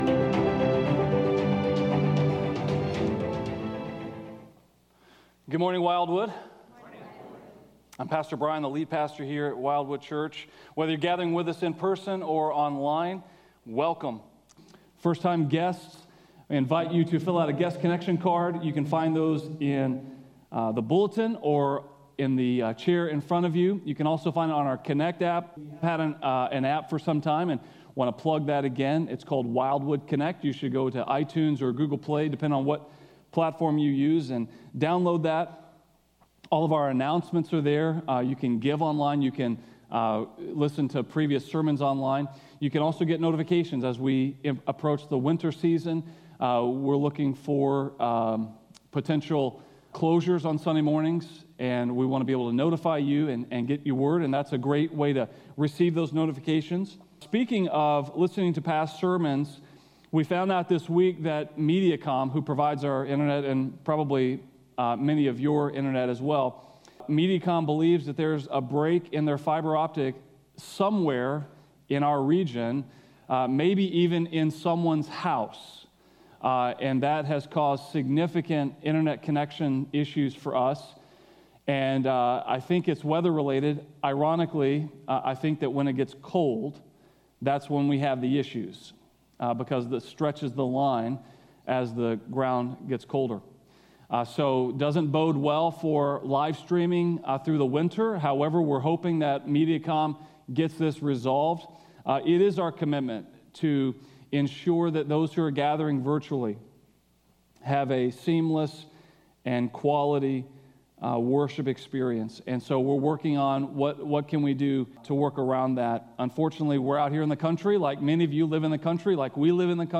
A message from the series "Behold the Glory."